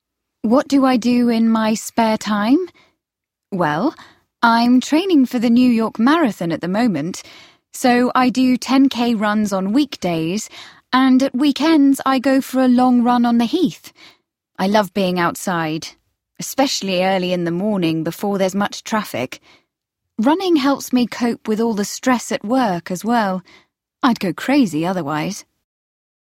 Then listen to four people from different parts of England.
Just listen to their accents.